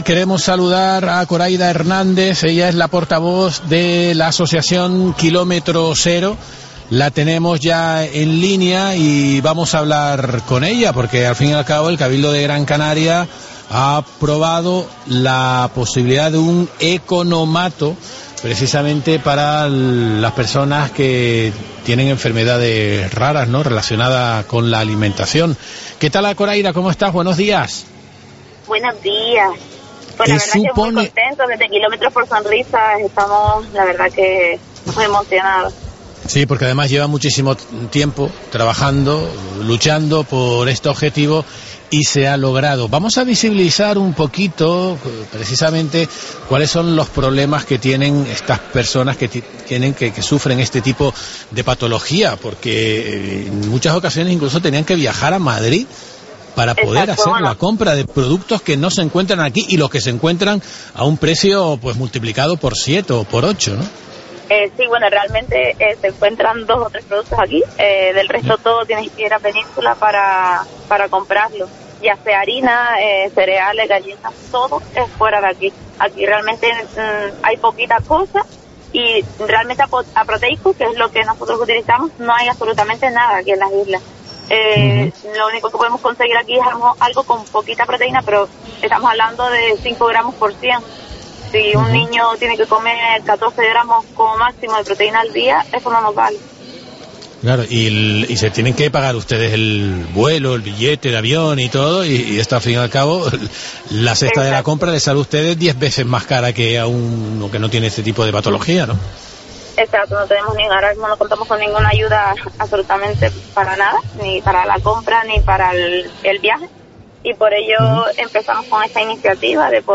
Nos lo contaba en Herrera en COPE Gran Canaria